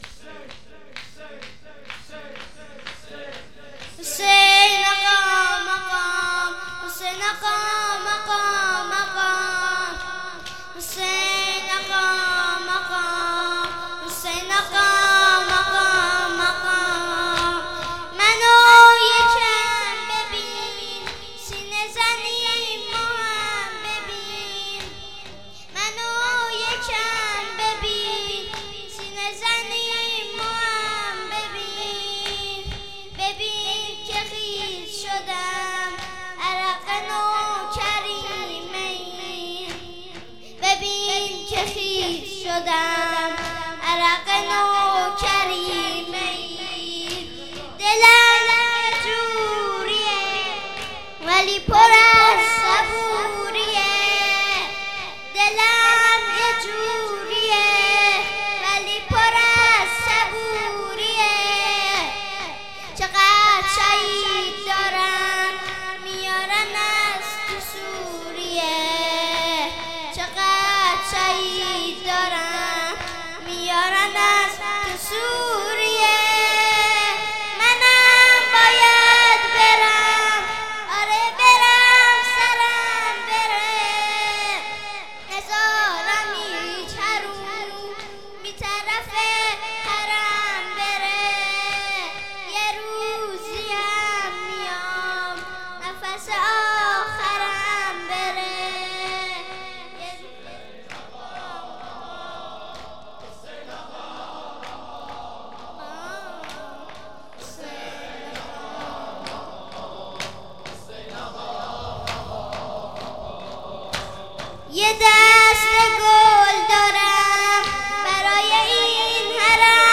خیمه گاه - هیئت قتیل العبرات - مداحی